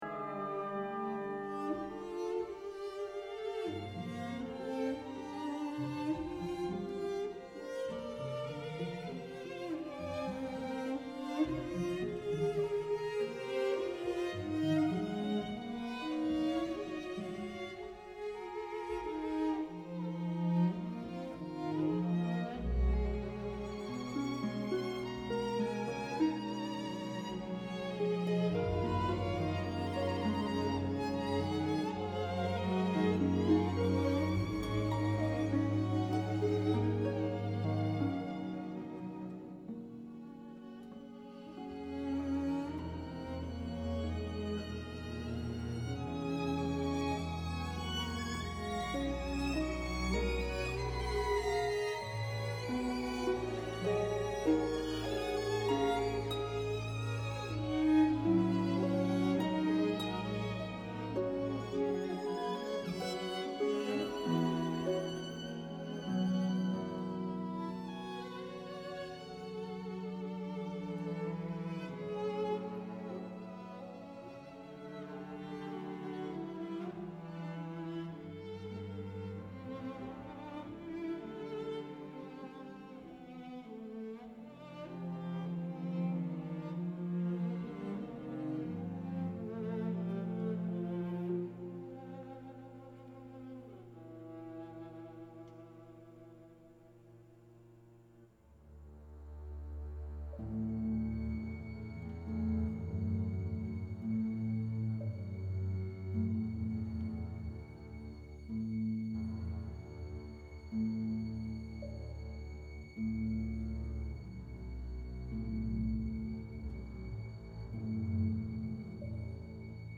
Concerto for Orchestra 管弦樂協奏曲 32 minutes